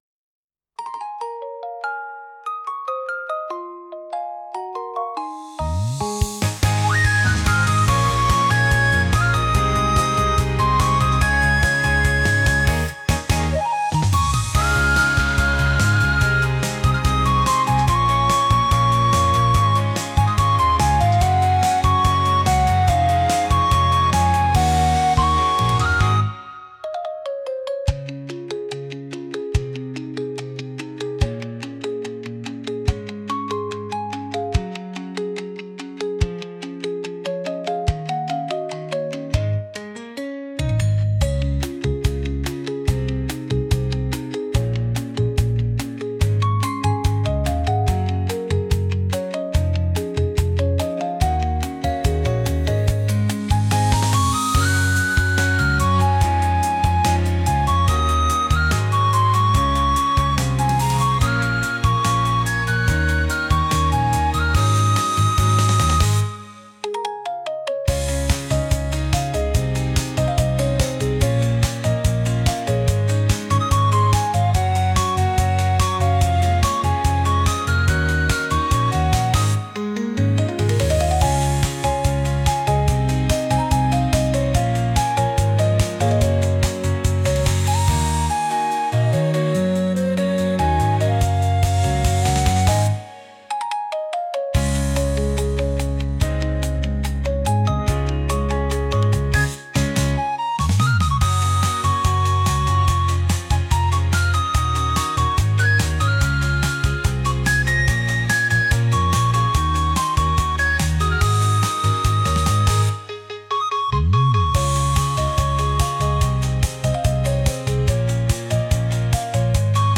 爽やかさを併せ持ったリコーダーとオルゴールの音色の音楽です。